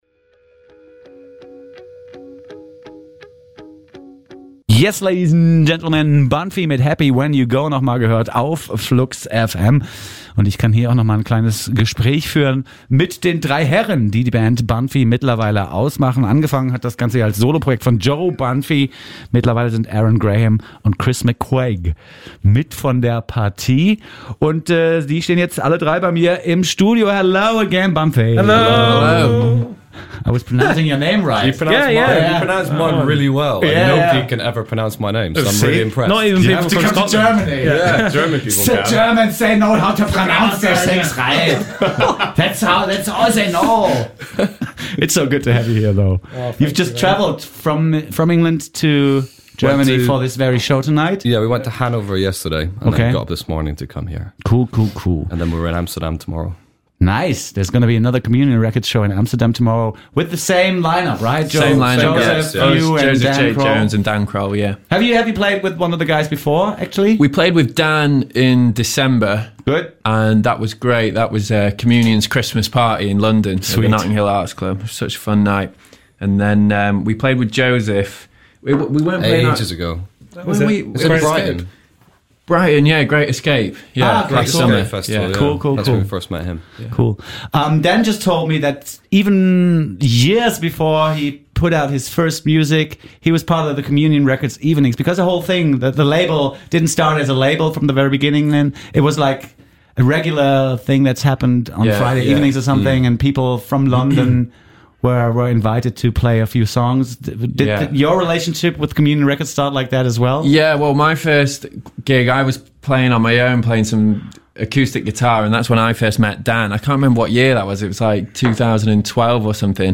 Alle Künstler kommen vor der Session heute Abend im FluxBau noch bei uns im Studio zum Interview vorbei.